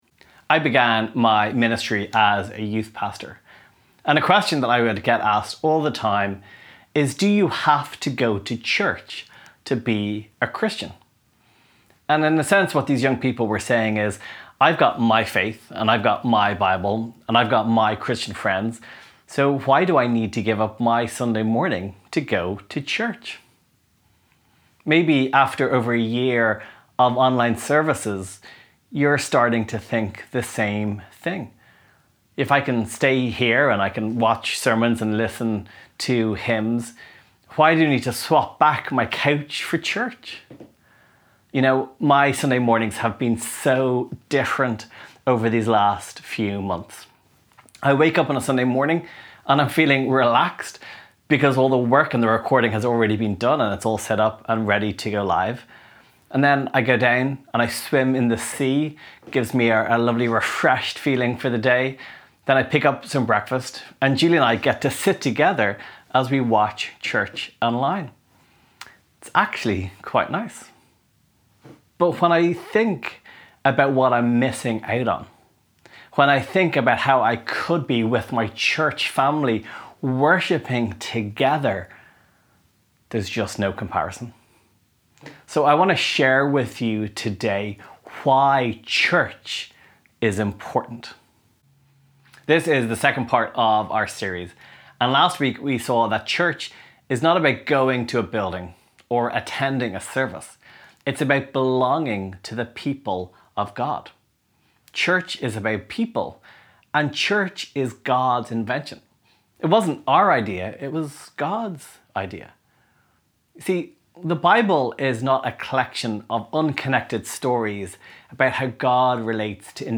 Downloads Download ‘Why is Church Important?’ Sermon MP3 *NEW* 1 Corinthians Reading Plan Share this: Share on X (Opens in new window) X Share on Facebook (Opens in new window) Facebook Like Loading...